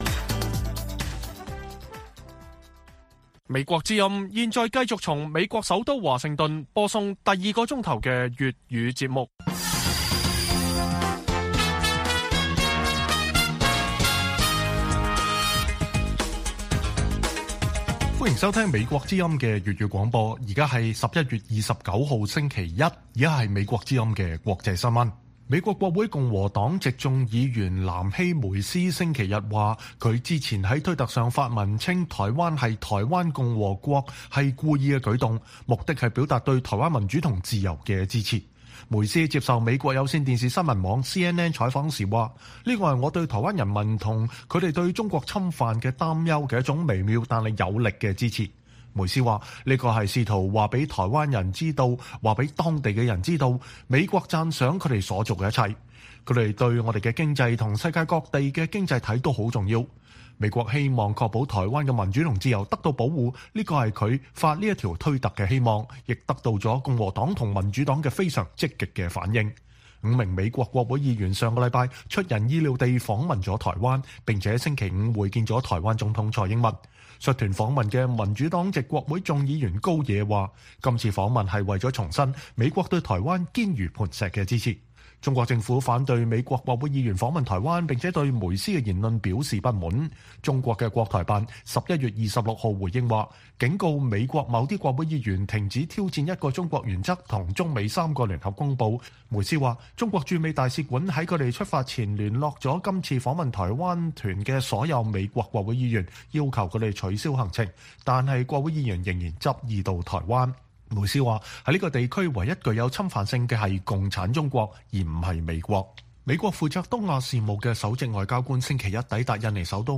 粵語新聞 晚上10-11點: 美國議員稱“台灣共和國”推文措辭是有意為之